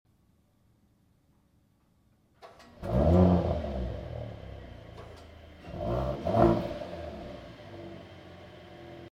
Sound of the Mercedes GLE sound effects free download
Sound of the Mercedes GLE 53 AMG.